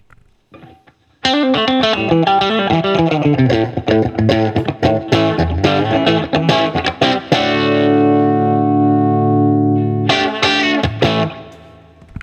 You can certainly hear the chambers affecting the sound which may be what Les Paul purists dislike about the tone, but I really like the complex tone delivered by the Bluesbird.
All recordings in this section were recorded with an Olympus LS-10. Amp is an Axe-FX set to “Basic Brit 800” with no effects, or the “Backline” setting which is a “Brownface” and a “US Lead 1”.
Guild-97-Bluesbird-BridgeRiff.wav